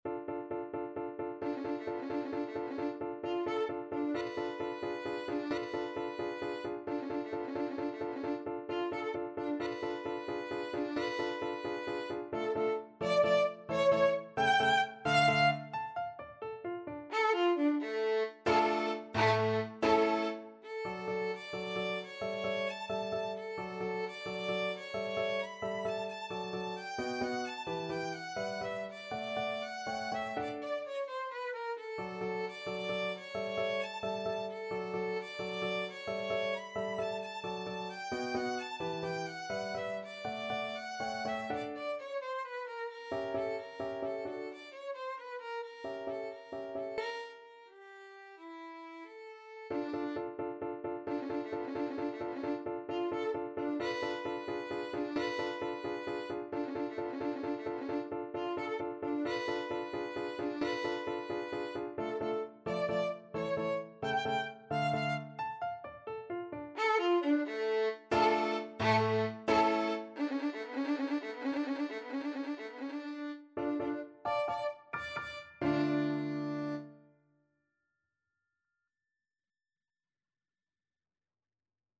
3/8 (View more 3/8 Music)
Classical (View more Classical Violin Music)